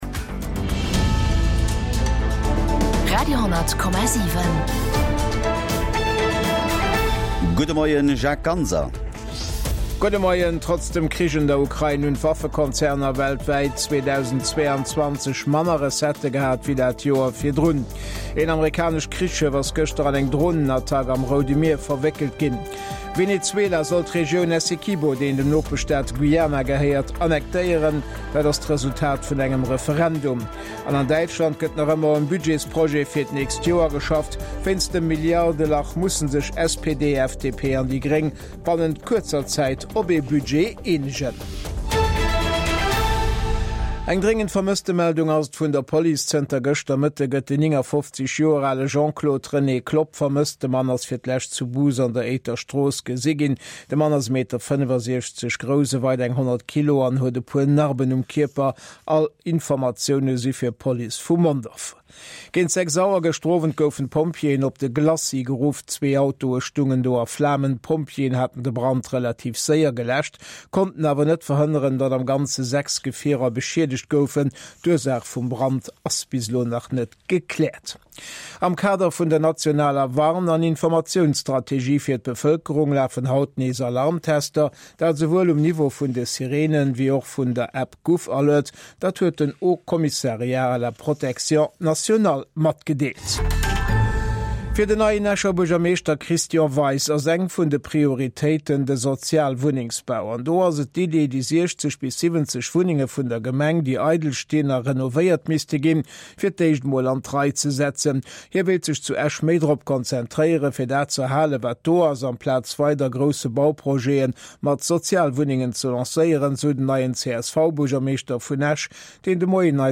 National an international Noriichten